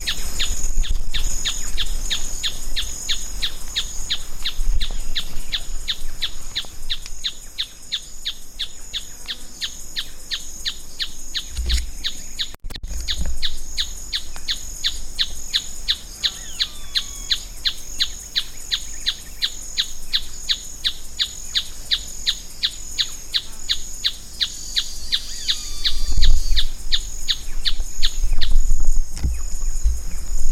Planalto Tapaculo (Scytalopus pachecoi)
Life Stage: Adult
Province / Department: Misiones
Location or protected area: Ruta 15 Biosfera Yaboti
Condition: Wild
Certainty: Recorded vocal